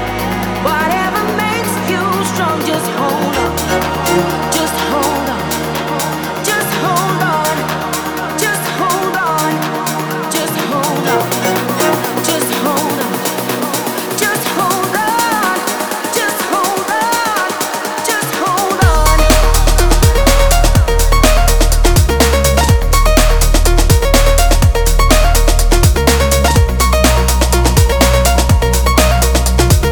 • Electronic